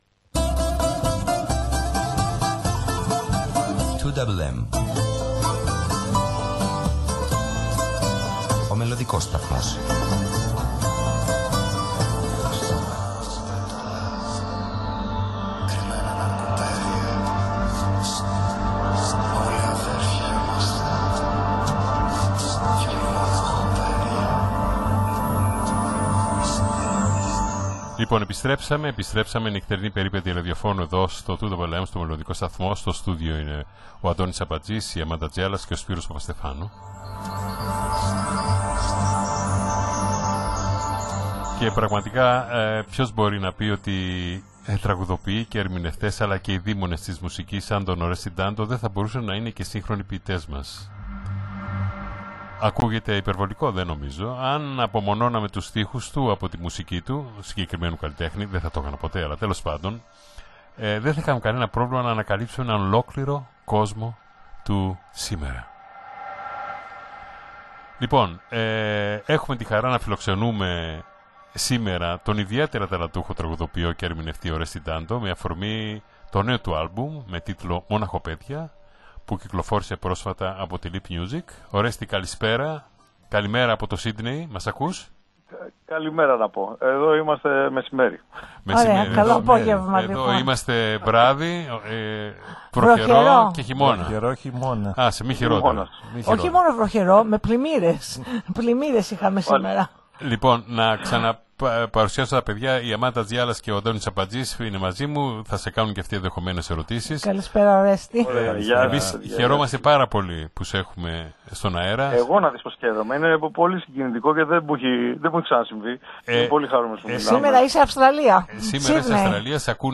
μίλησε σε ζωντανή σύνδεση